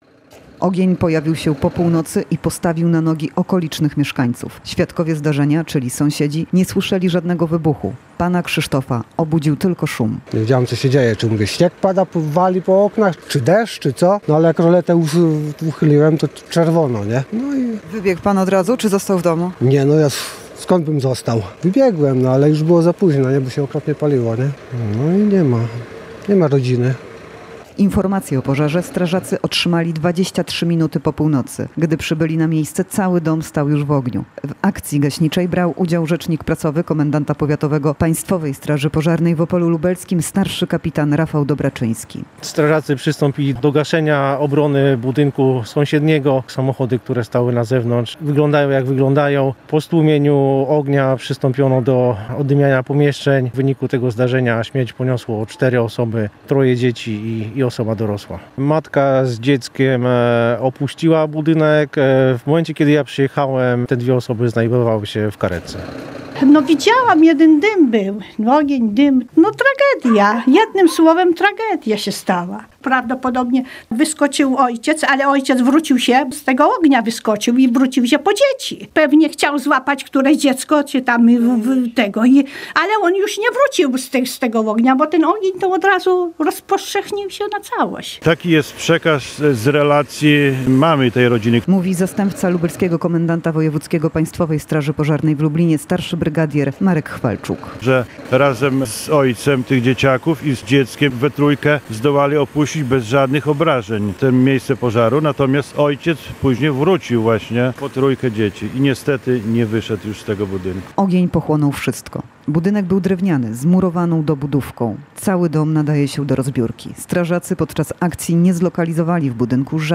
Jeszcze w chwili trwających działań strażaków, na miejscu była nasza reporterka.